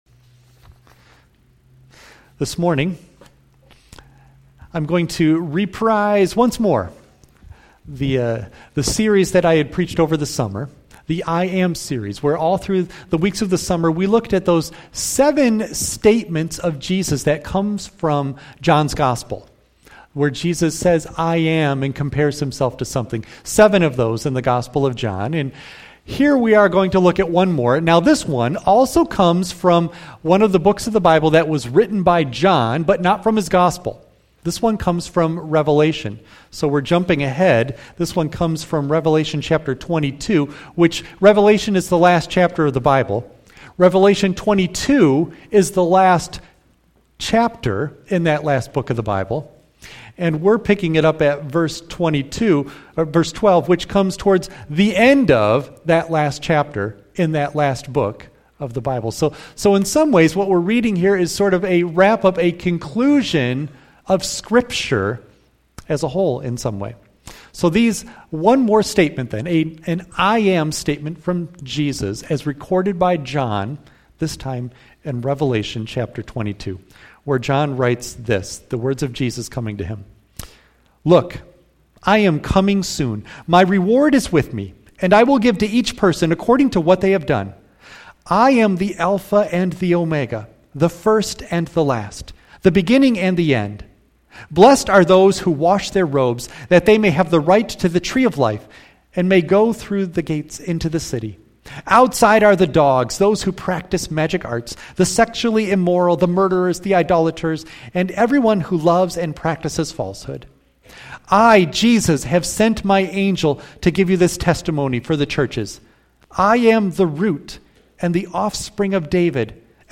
Revelation 22:12-17 Service Type: Sunday AM Bible Text